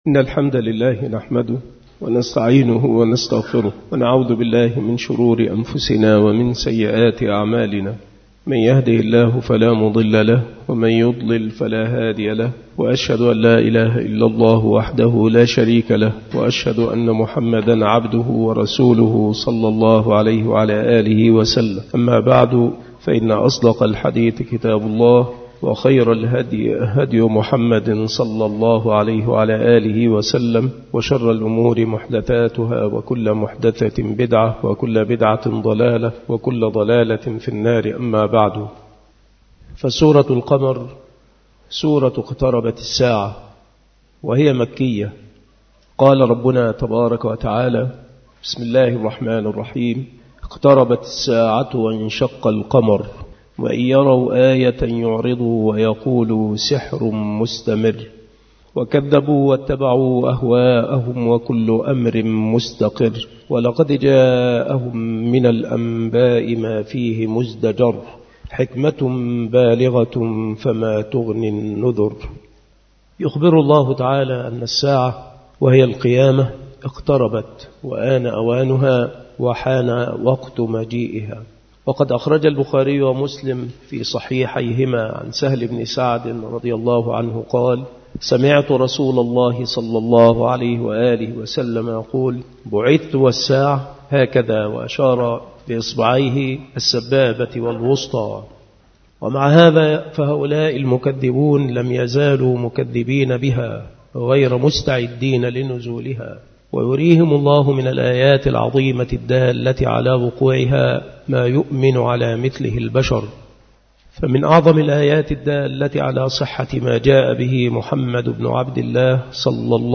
التصنيف التفسير
مكان إلقاء هذه المحاضرة بالمسجد الشرقي بسبك الأحد - أشمون - محافظة المنوفية - مصر